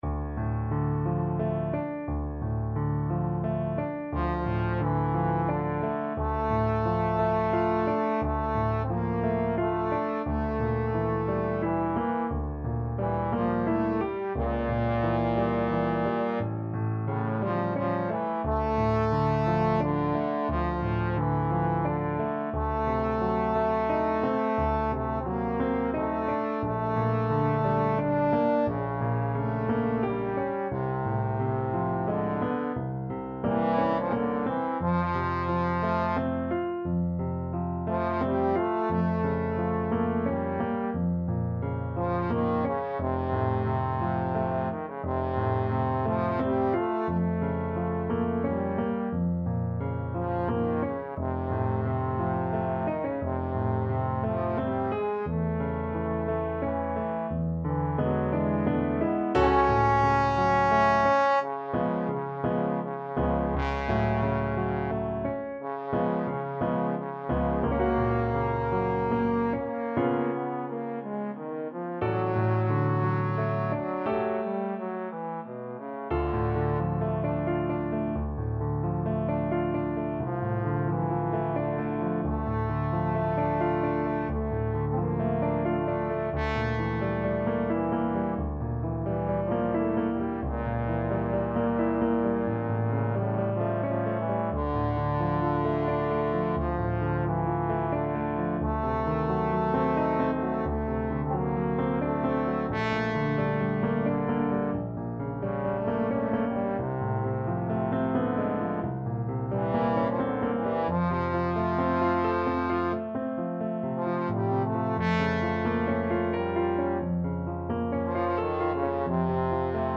Play (or use space bar on your keyboard) Pause Music Playalong - Piano Accompaniment Playalong Band Accompaniment not yet available transpose reset tempo print settings full screen
Trombone
D minor (Sounding Pitch) (View more D minor Music for Trombone )
3/4 (View more 3/4 Music)
~ = 88 Malinconico espressivo
Classical (View more Classical Trombone Music)